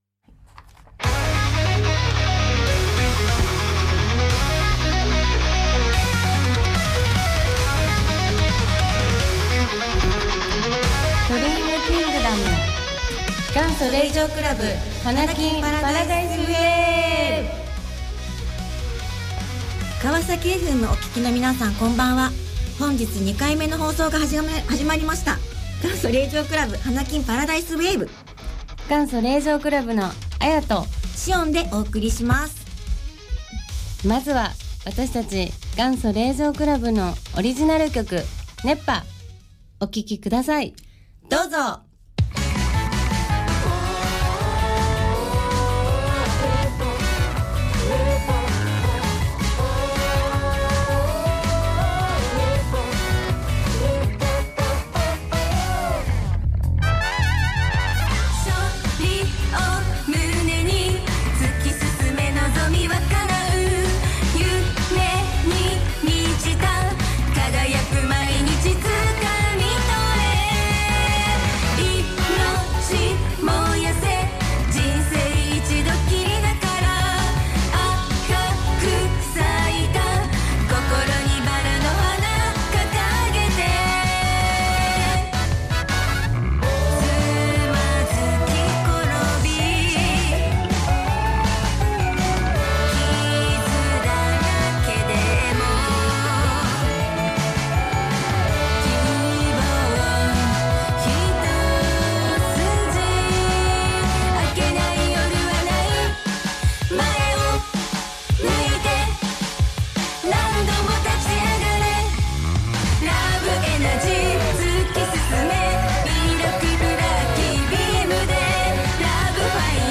第２回目となる「元祖☆令嬢倶楽部の華金☆パラダイスWAVE」、ファンの方も駆けつけてくれて温かいスタートとなりました。